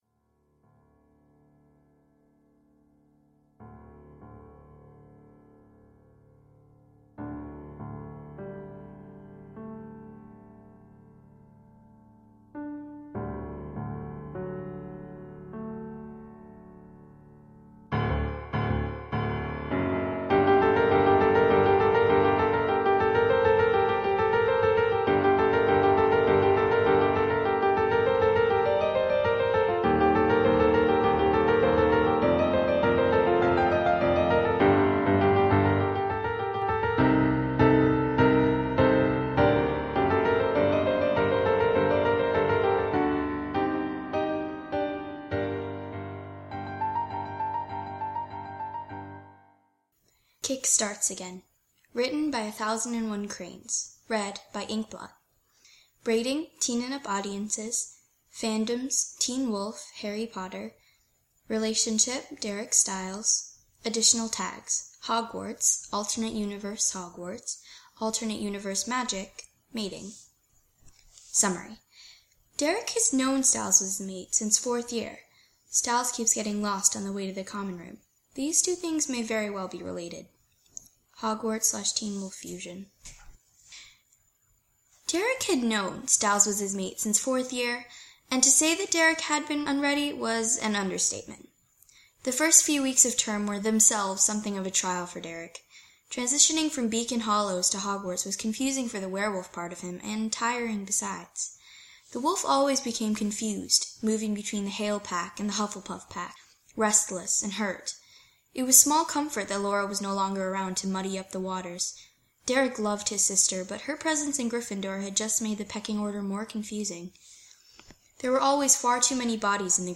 [podfic] kickstarts again